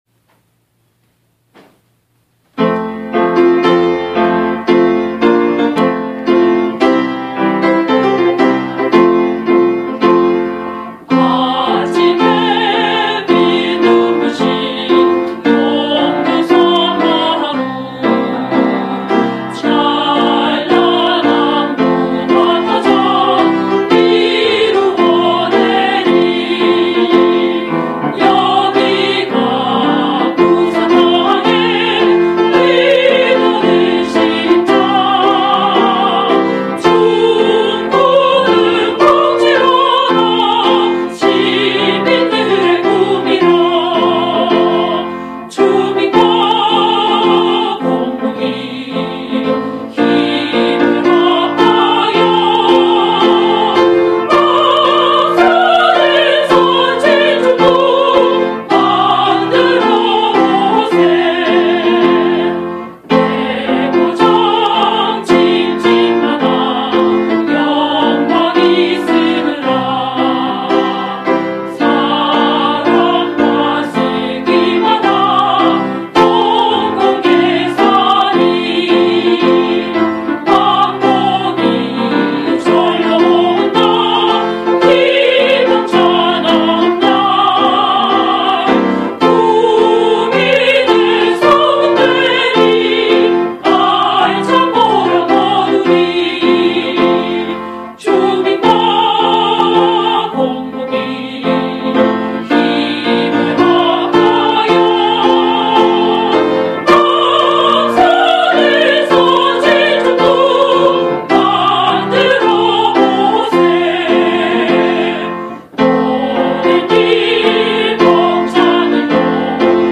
밝고 힘차게 확대보기